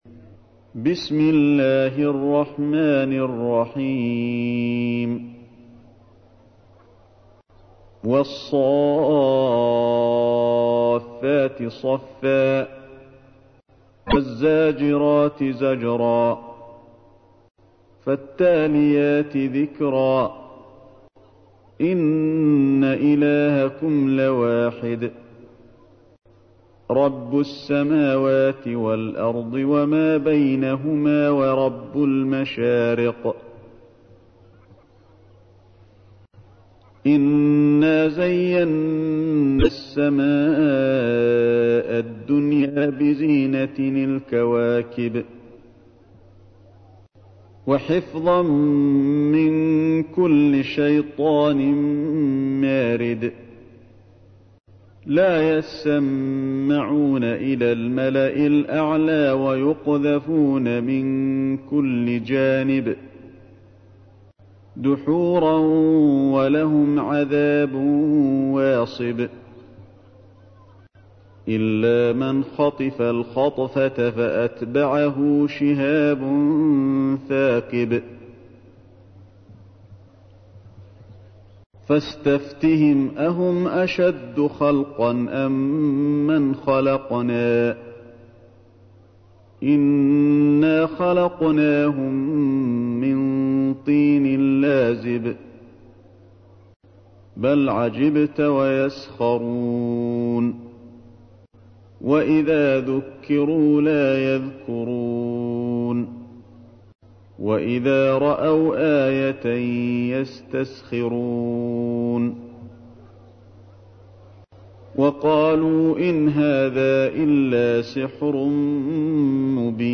تحميل : 37. سورة الصافات / القارئ علي الحذيفي / القرآن الكريم / موقع يا حسين